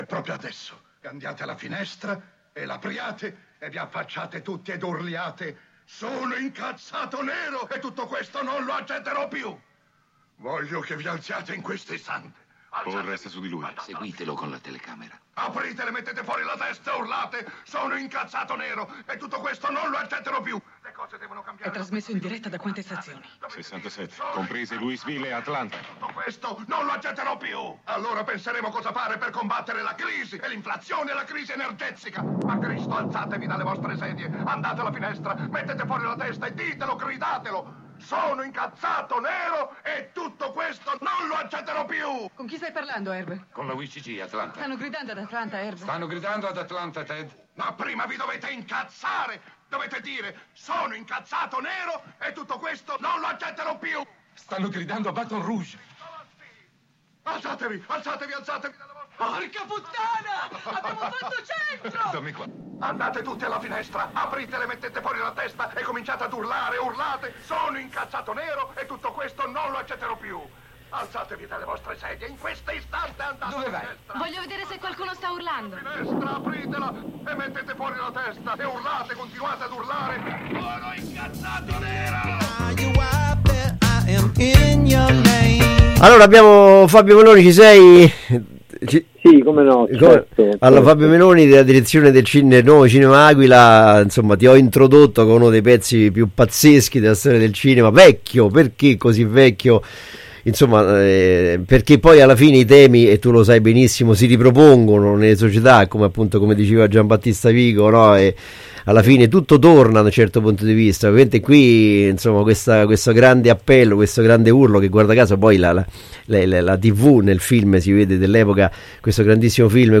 Nuovo Cinema Aquila, l’Intervista